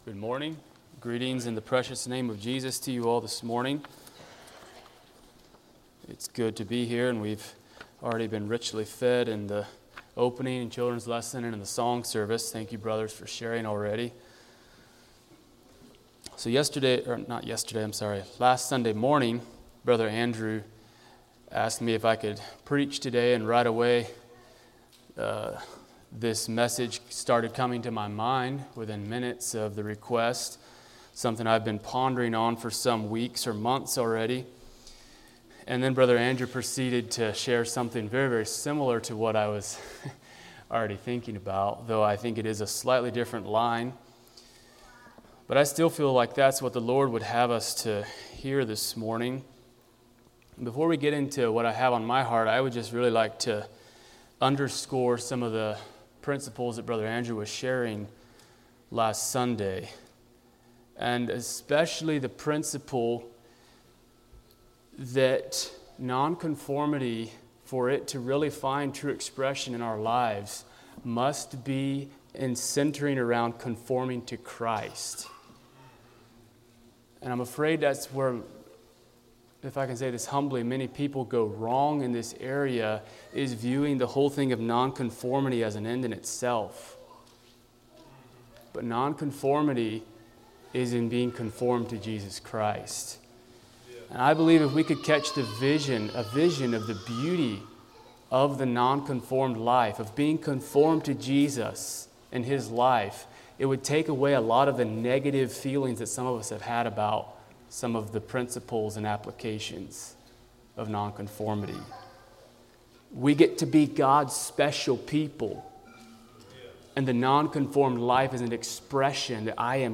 Sermons - Blessed Hope Christian Fellowship